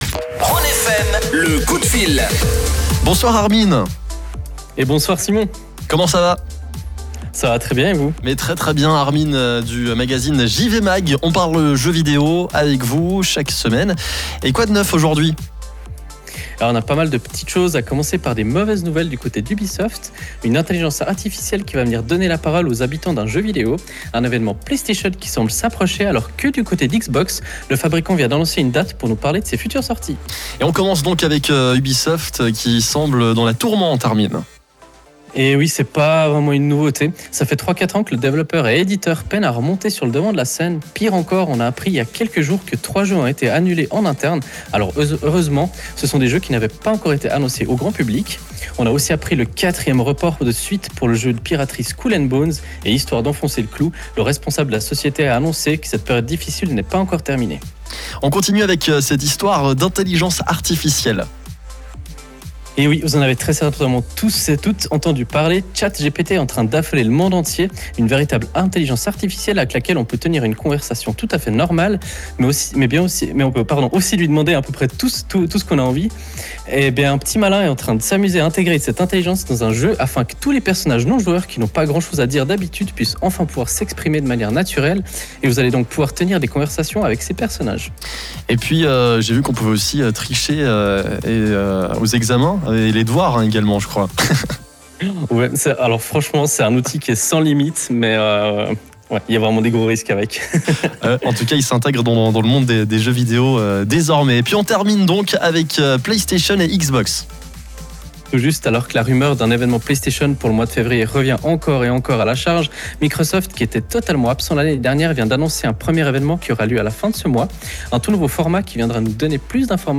Vous pouvez réécouter le direct via le flux qui se trouve juste en dessus.